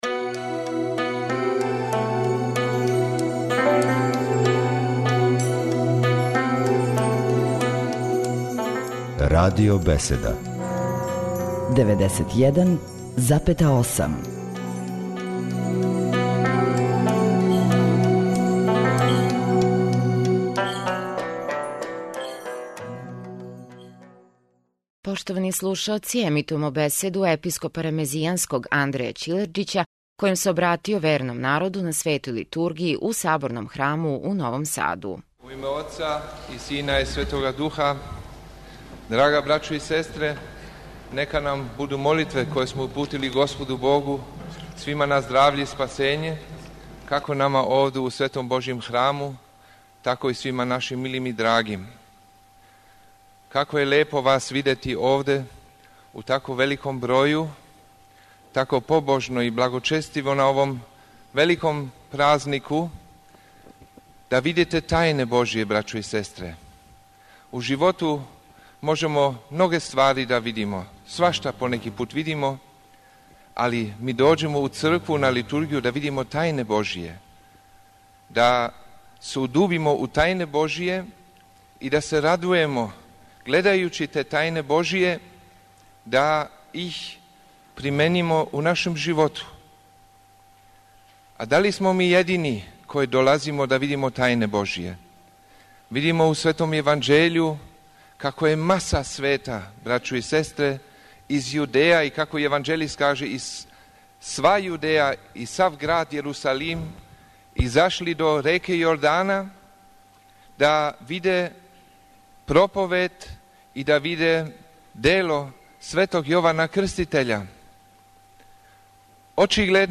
Прослава Јовандана у Светогеоргијевском храму у Новом Саду
Студенти Богословског факултета у Београду и монаси Светоархангелског манастира у Ковиљу, благољепијем и појањем, украсили су данашње евхаристијско славље.